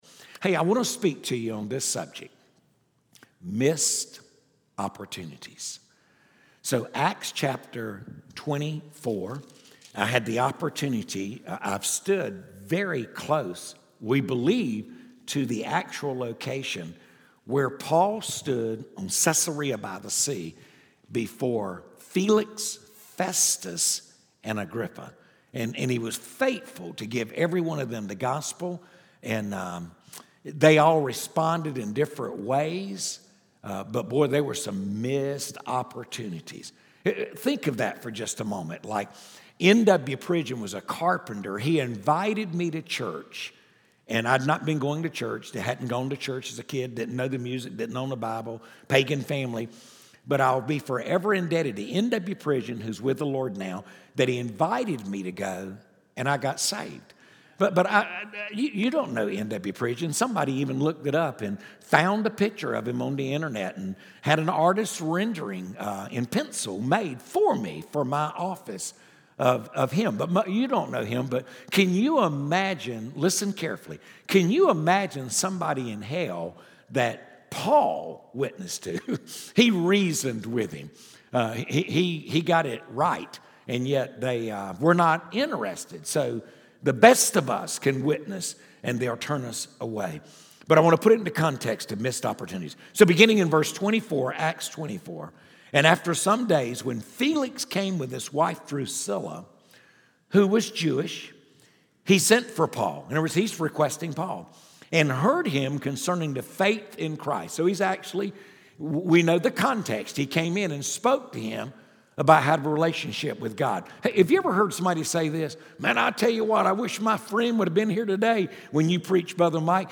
From the evening session of the Real Momentum Conference on Friday, August 2, 2019